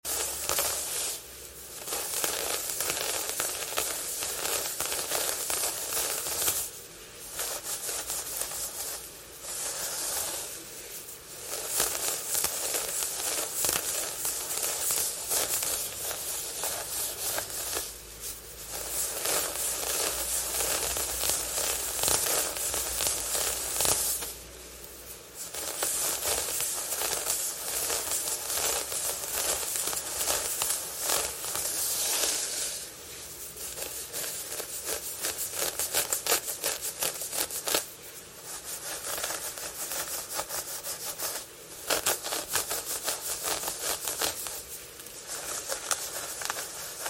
Scratch with long nails Asmr sound effects free download
Scratch with long nails Asmr scratching head